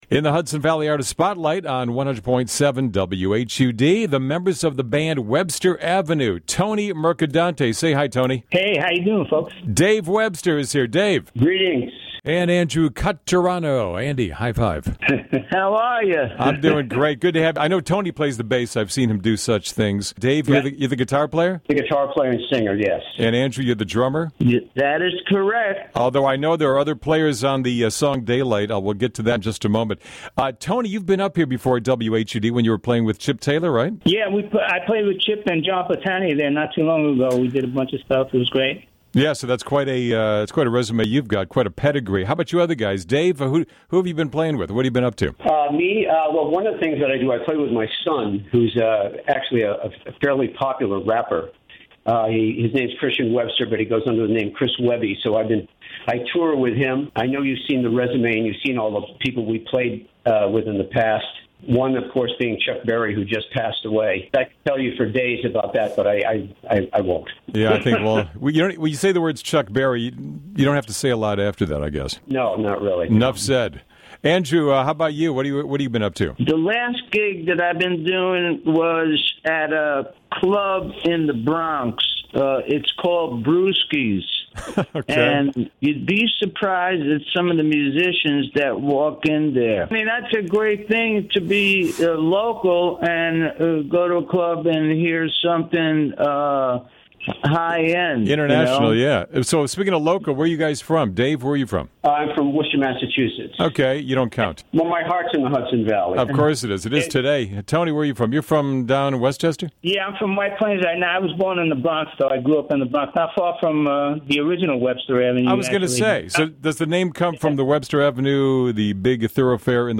Interview with Webster Ave